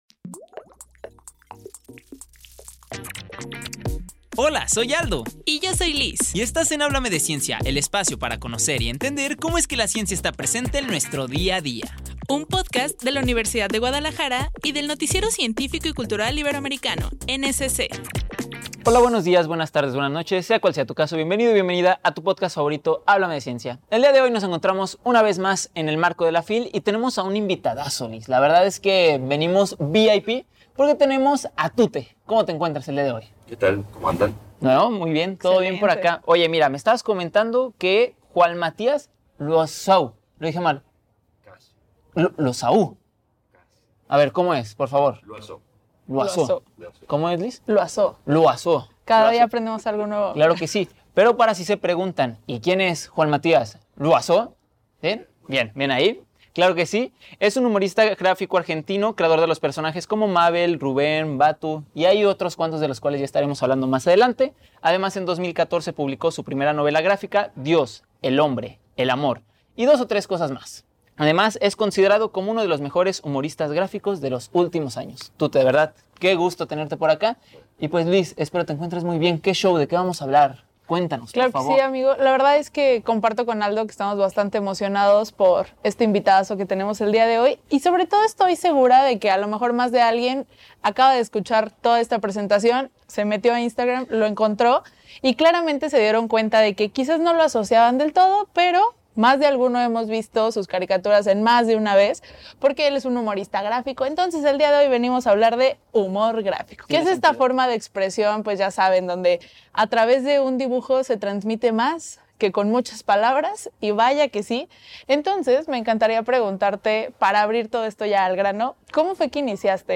El humor nos ayuda a imaginar, reflexionar y ponernos filosóficos. En este episodio nos acompaña Tute, el maestro del humor gráfico, donde hablamos de su trayectoria, de los entrañables Mabel y Rubén, y de lo que le espera a este arte en tiempos digitales. ¡Acompáñanos en esta conversación donde los trazos cuentan historias!